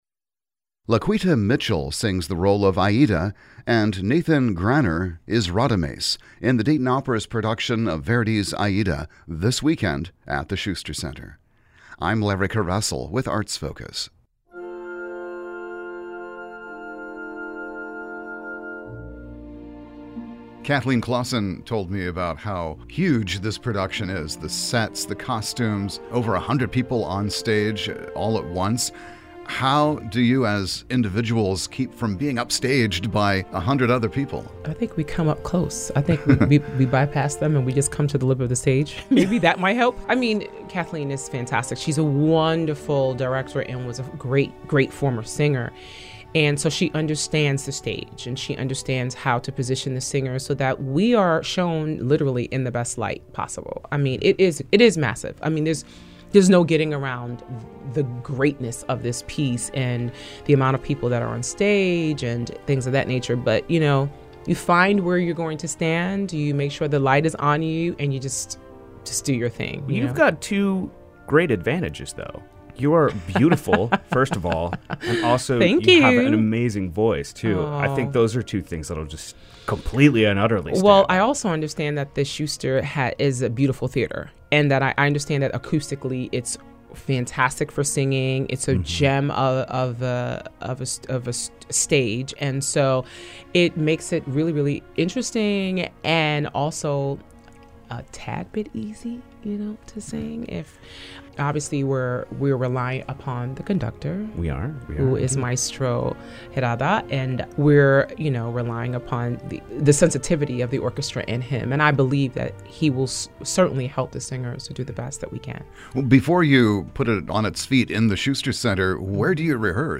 Each segment features a guest from the regional arts community discussing current activities, such as concerts, exhibitions and festivals.